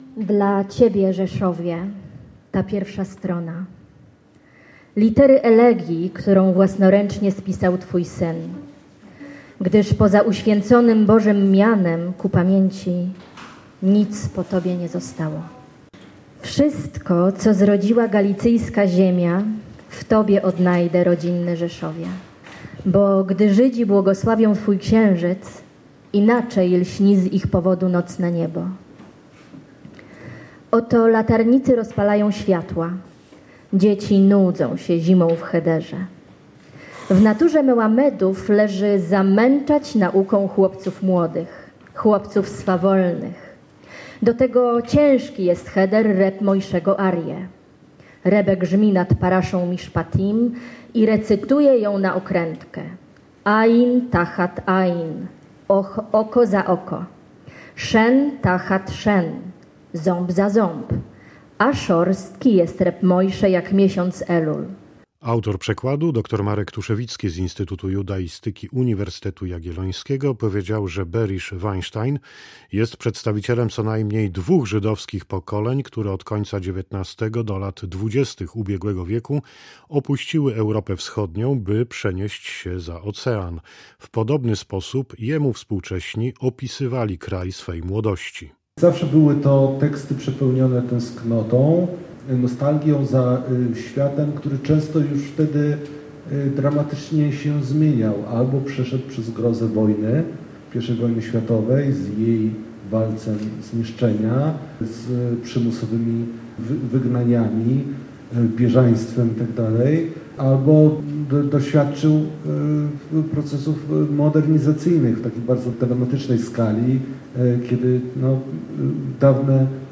W Rzeszowie odbyło się premierowe czytanie fragmentów poematu „Rajsze”